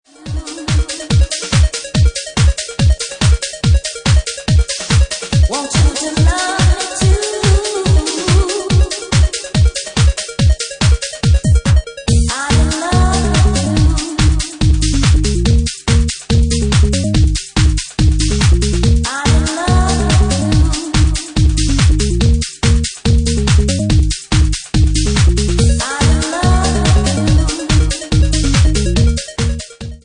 Bassline House at 143 bpm